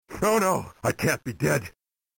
And what a gag, it comes packaged with my second favourite voiceclip in the entire game.